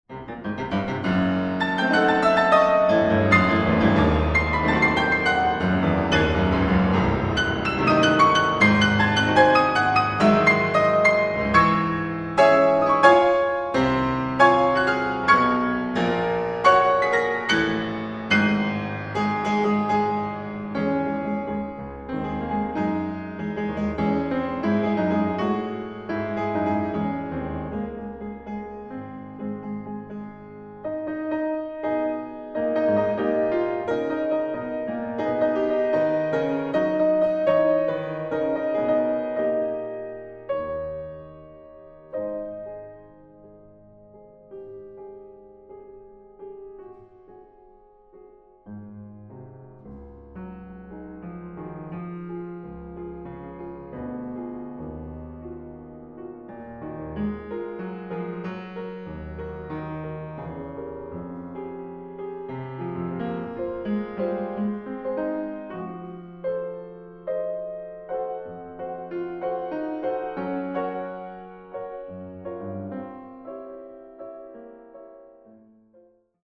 Allegro maestoso (F minor)
Classical